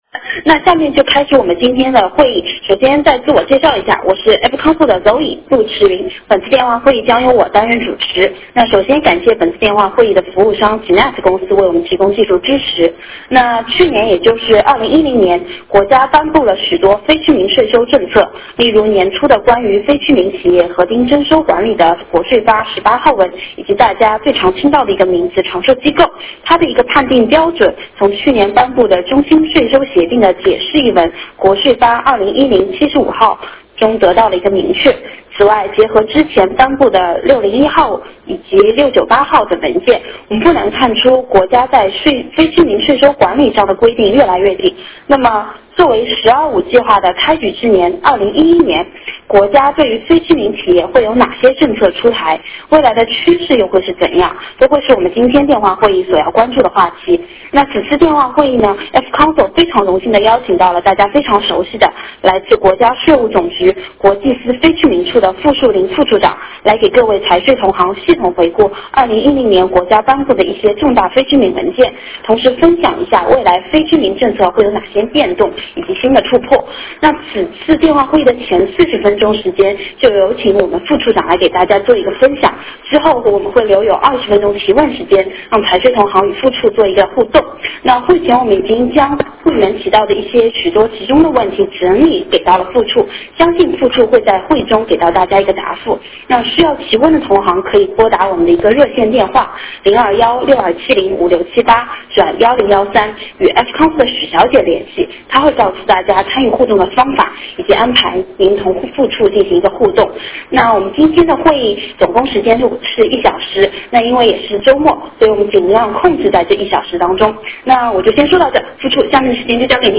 电话会议
Q&A